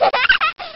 Toddler Giggle Sound Effect Free Download
Toddler Giggle